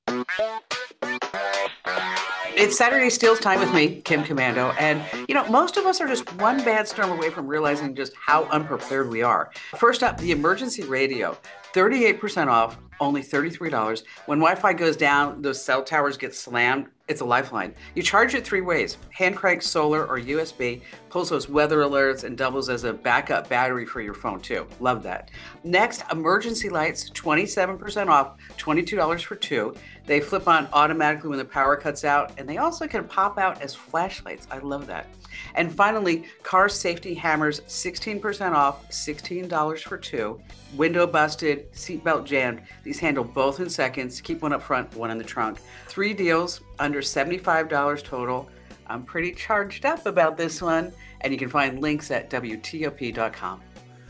Kim Komando breaks down the hottest deals this weekend.